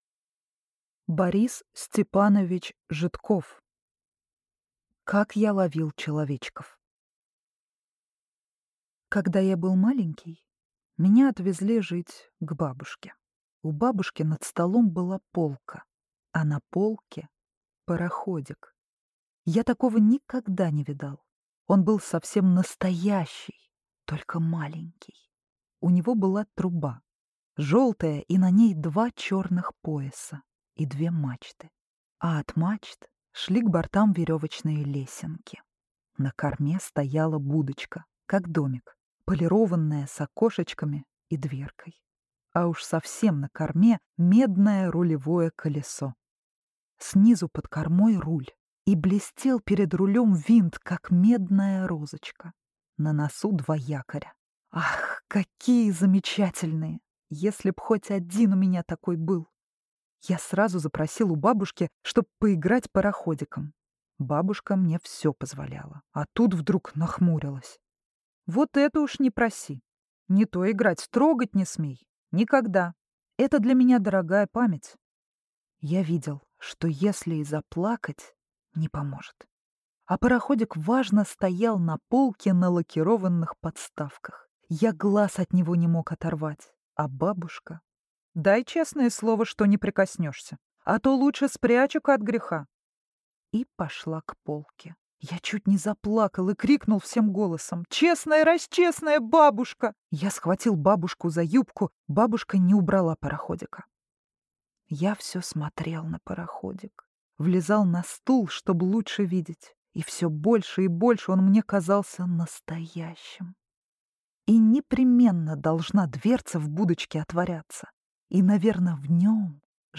Аудиокнига Как я ловил человечков | Библиотека аудиокниг